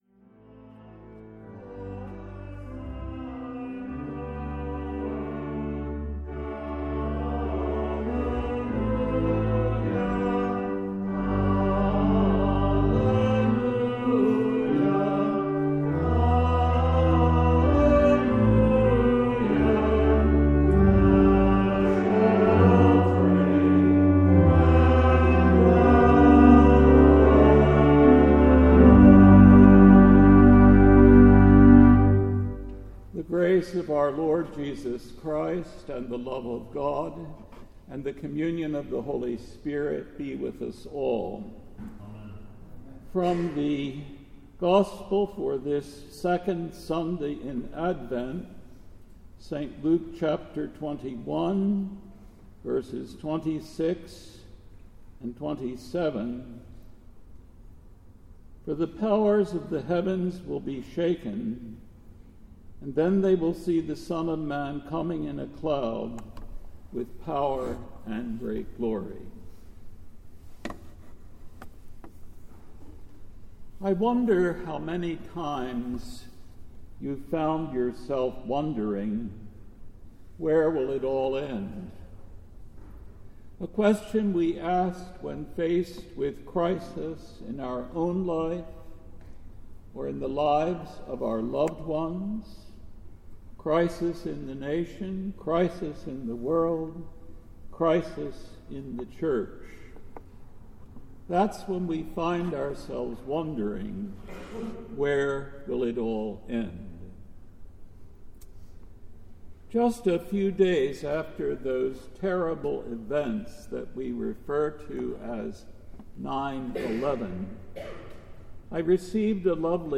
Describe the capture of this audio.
The Second Sunday in Advent December 10, 2019 AD